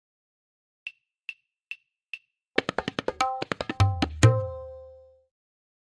5-Matra Mukhra
M4.2-Mukhra-1x-Click.mp3